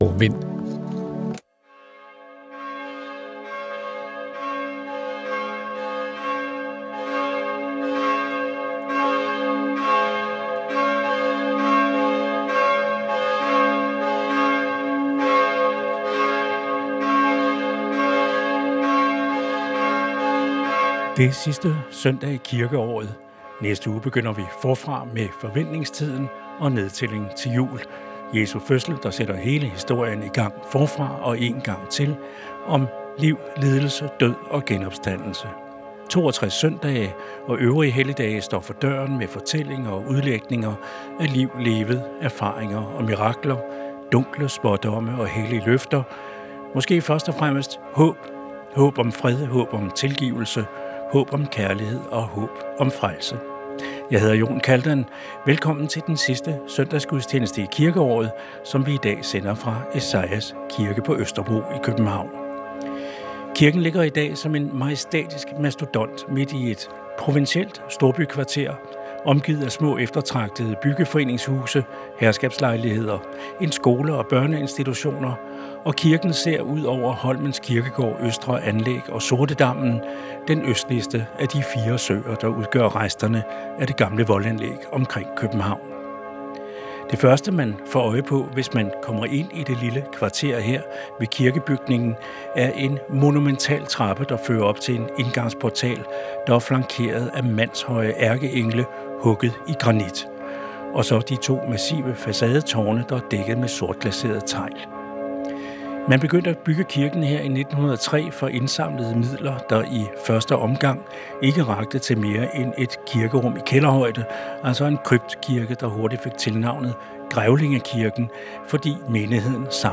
Direkte transmission af dagens højmesse fra en af landets kirker.